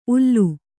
♪ ullu